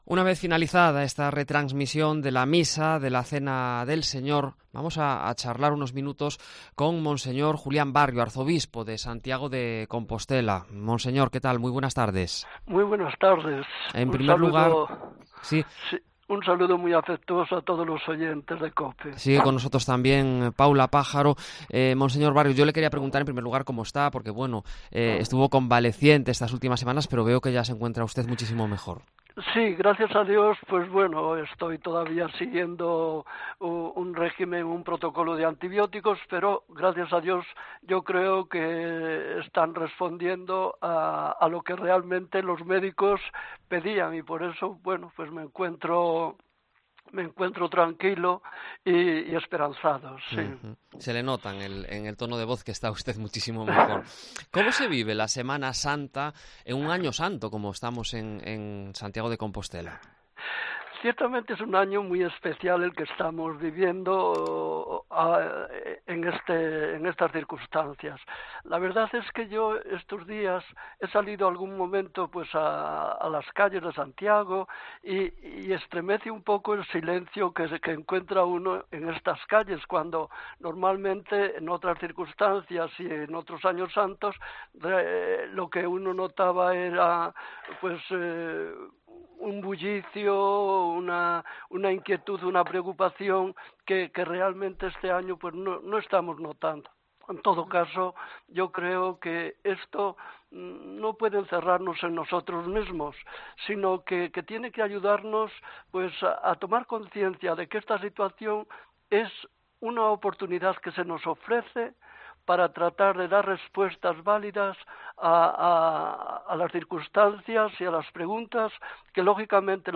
Podcast: entrevista con el arzobispo de Santiago tras la misa de la Última Cena